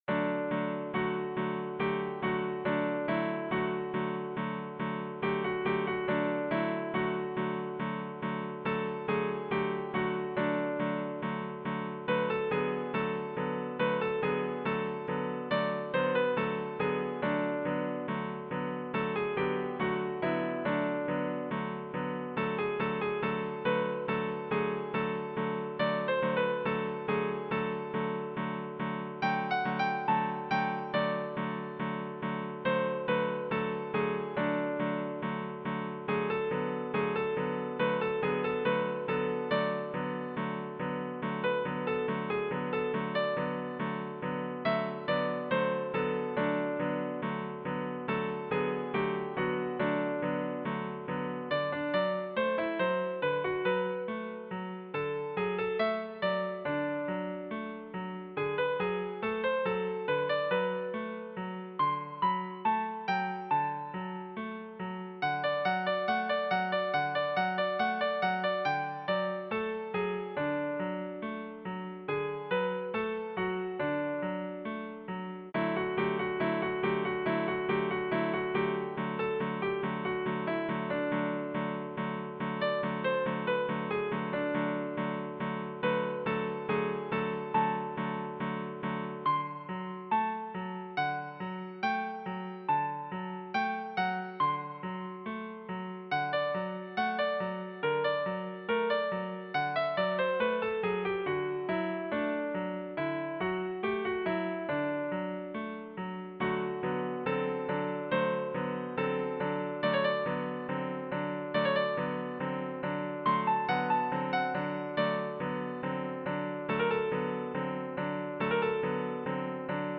mp3-Aufnahme: mit midi Instrument